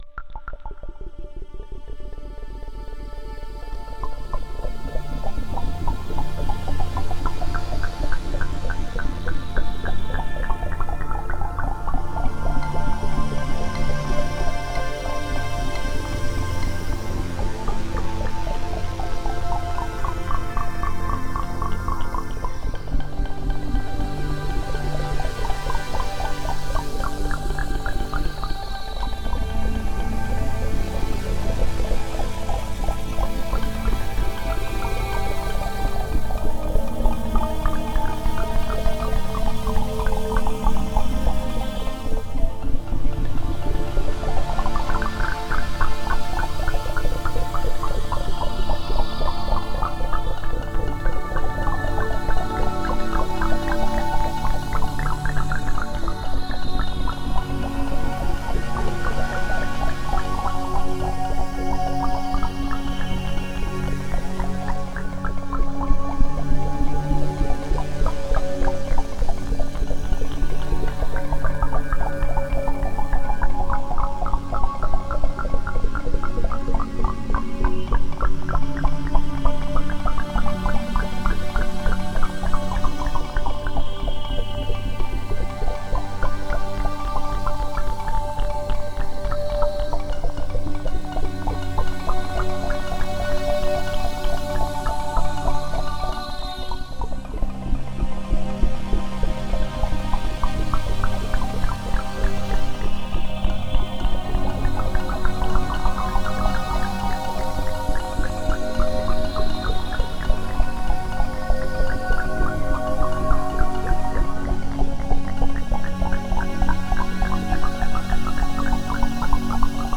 Духовная музыка Мистическая музыка Медитативная музыка